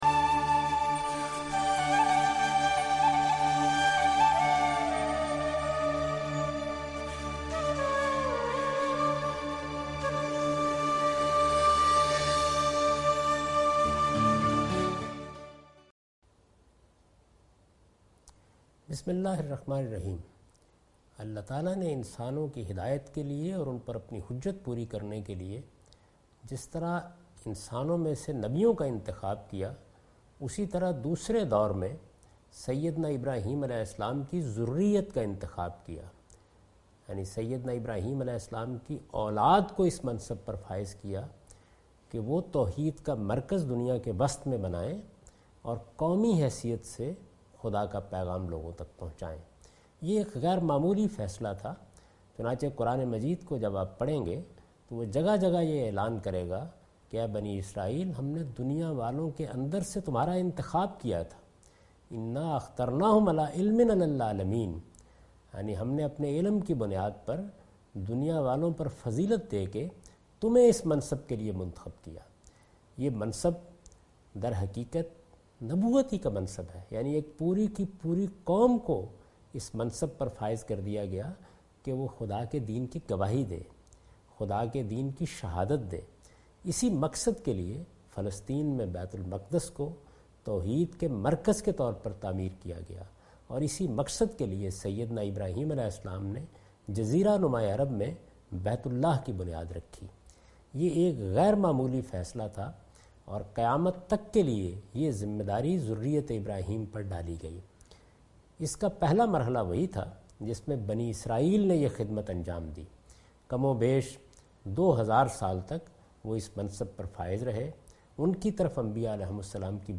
This series contains the lecture of Javed Ahmed Ghamidi delivered in Ramzan. He chose 30 different places from Quran to spread the message of Quran. In this lecture he discuss Muslim Ummah's responsibility in terms of Dawah (Dawat).